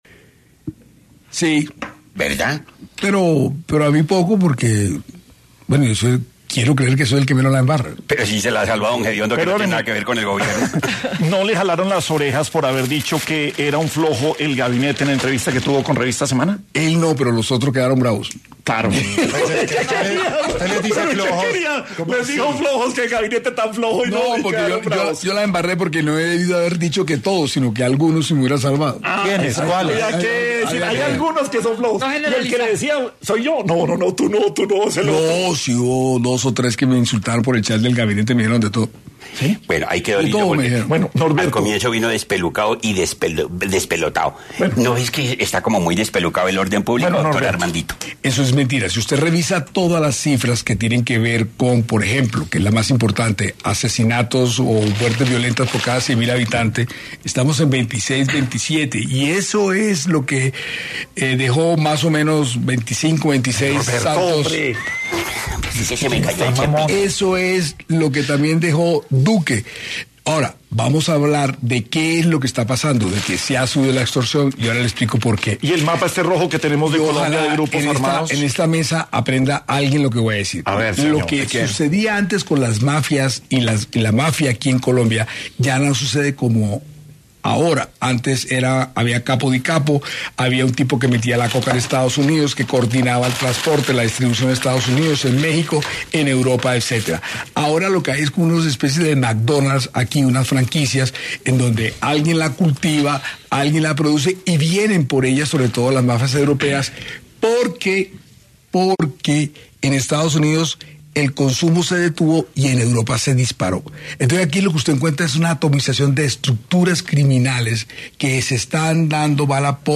En Sin Anestesia de Caracol Radio, estuvo el Ministro del Interior, Armando Benedetti, y habló sobre la situación del país en cuanto a temas de orden público y seguridad